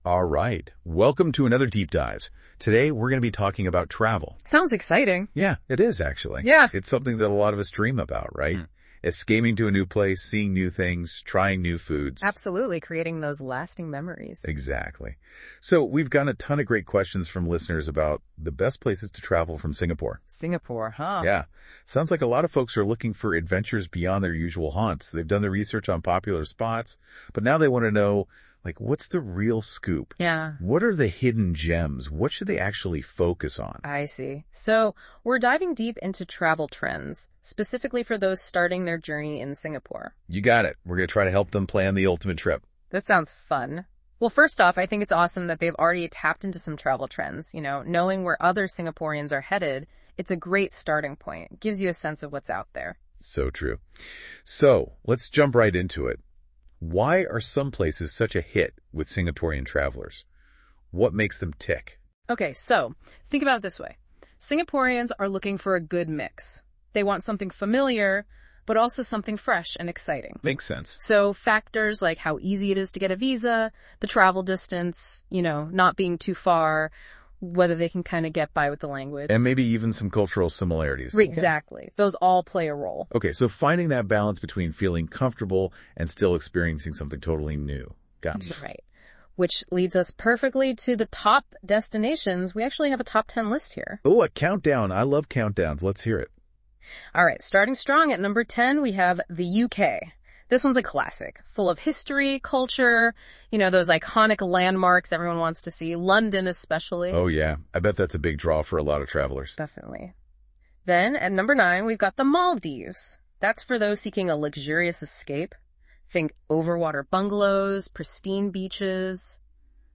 Participants in the discussion:
Conversation recording: